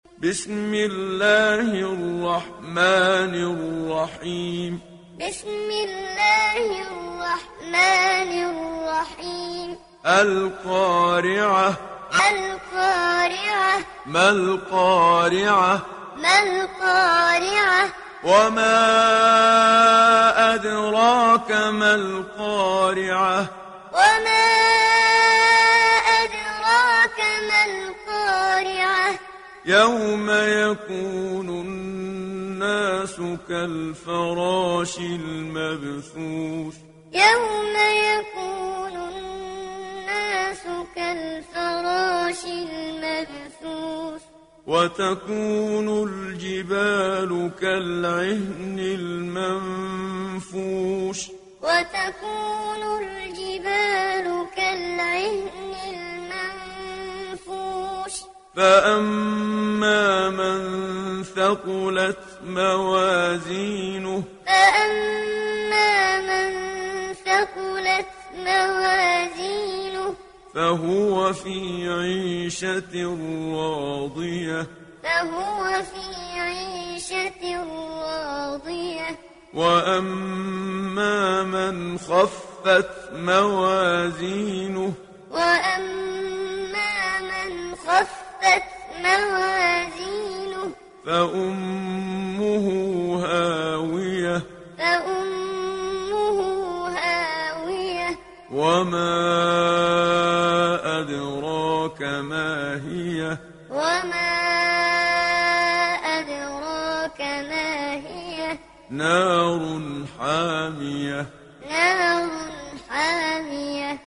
Muallim